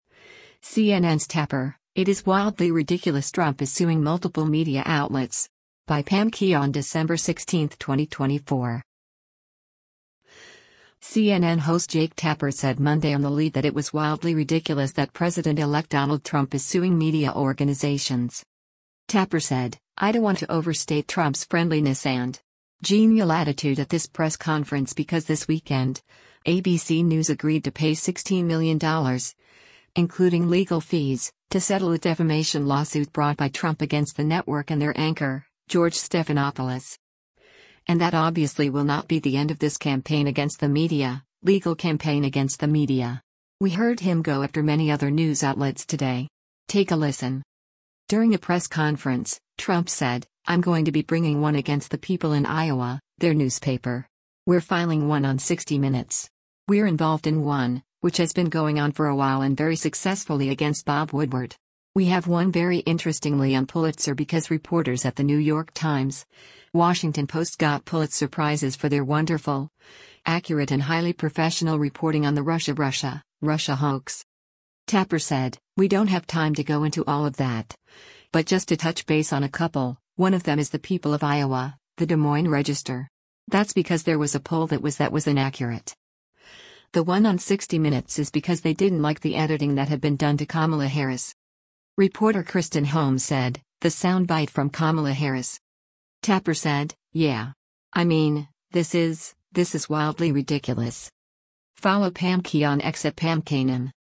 CNN host Jake Tapper said Monday on “The Lead” that it was “wildly ridiculous” that President-elect Donald Trump is suing media organizations.